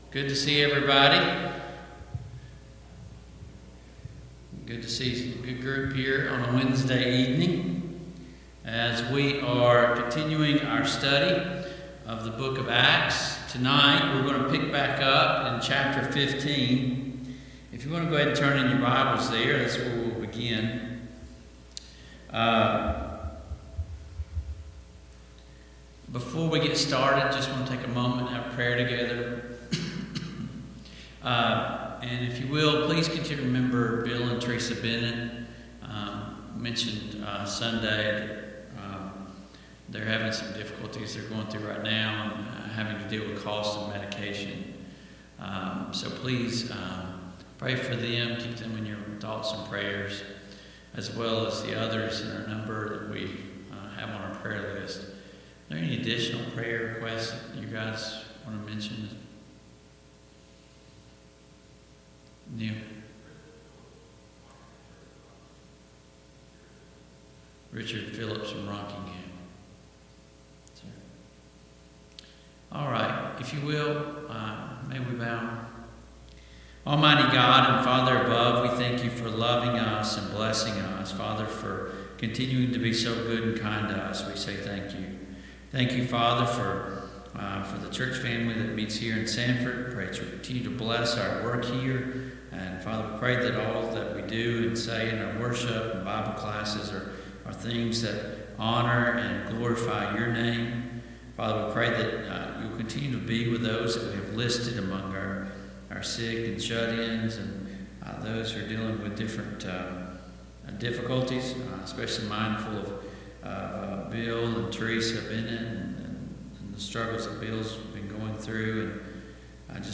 Passage: Acts 15:12-41 Service Type: Mid-Week Bible Study